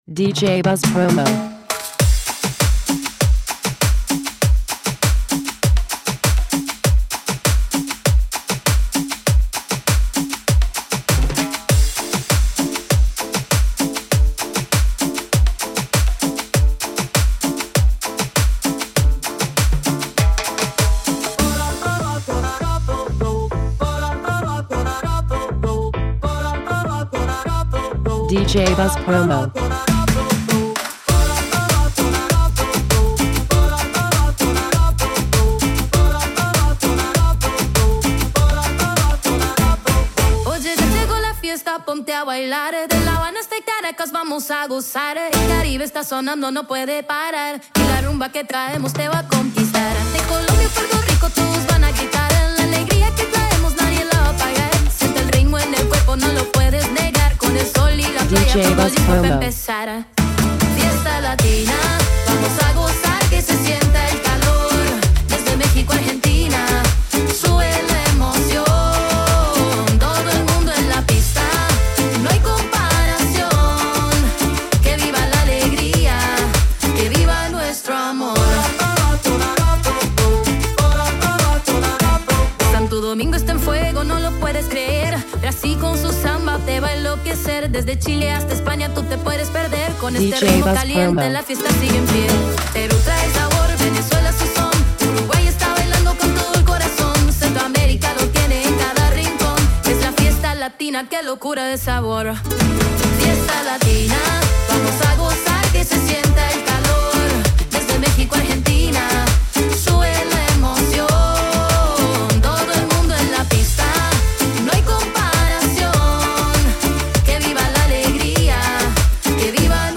Extended Mix